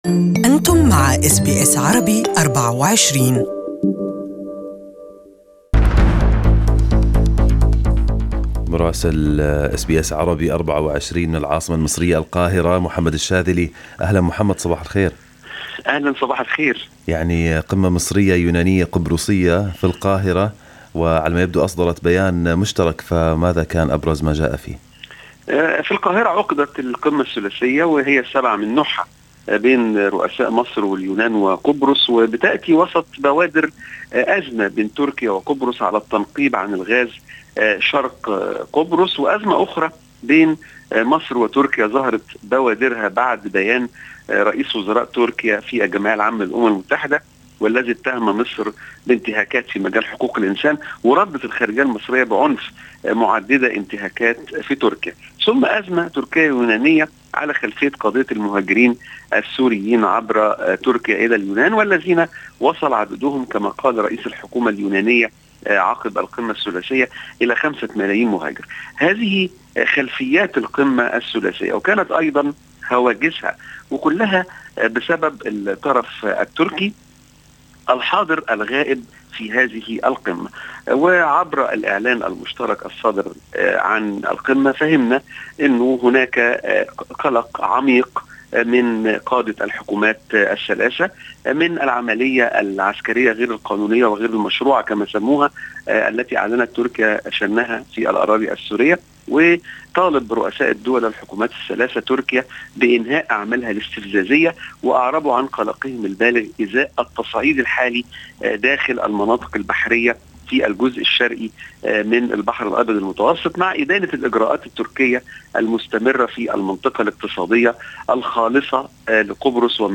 Listen to the full report from our correspondent in Egypt in Arabic above